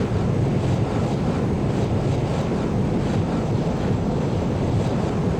escape2.wav